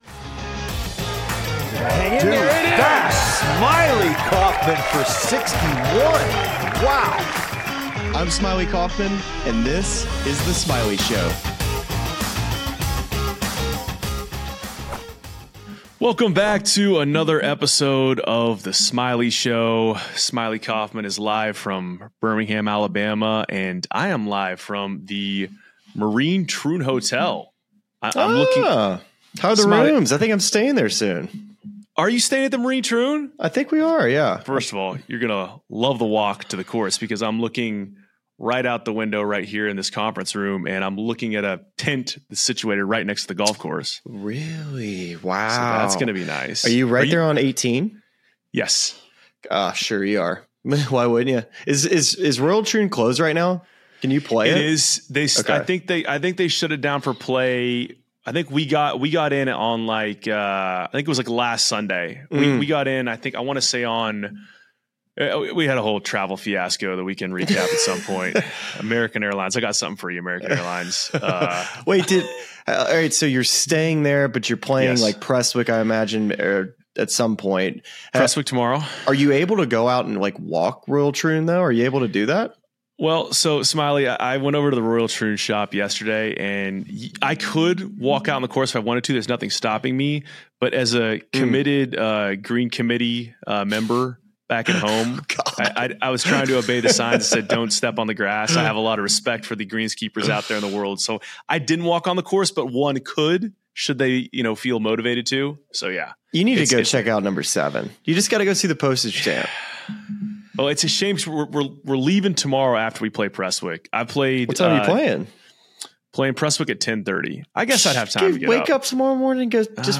live from Birmingham
reports from the Marine Troon Hotel